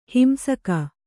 ♪ himsaka